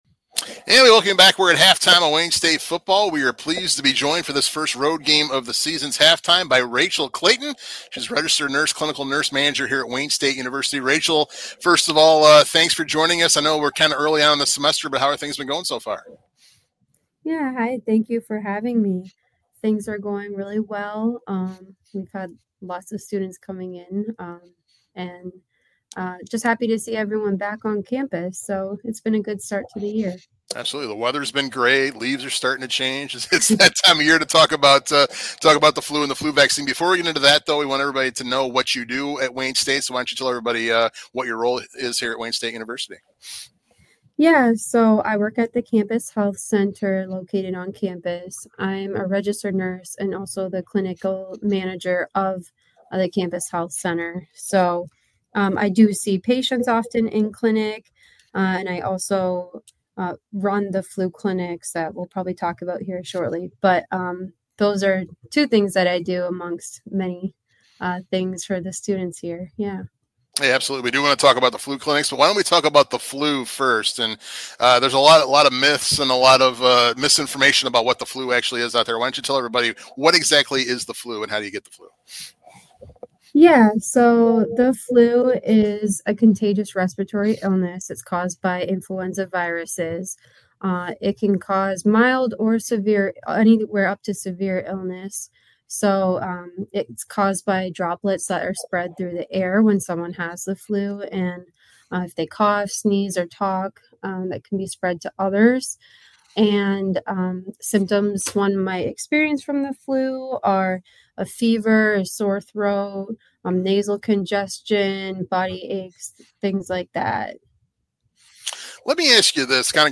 Halftime interview